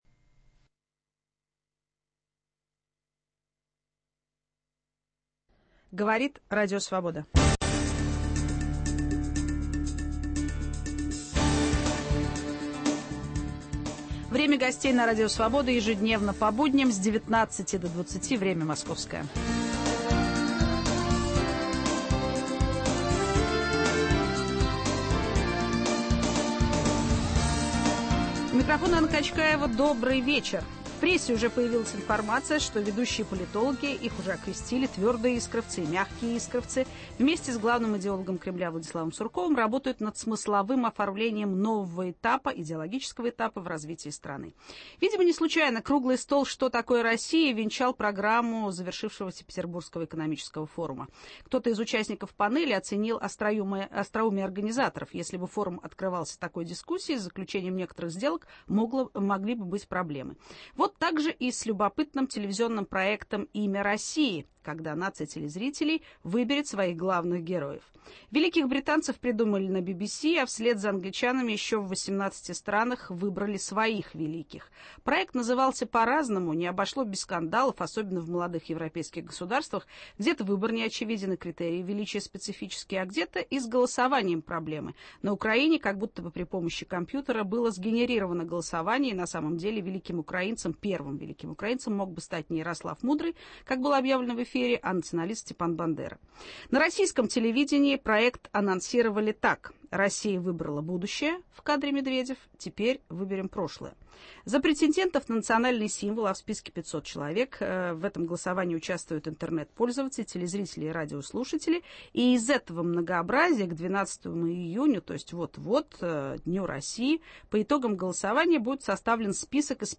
В гостях у Анны Качкаевой заместитель генерального директора ВГТРК, продюсер Александр Любимов.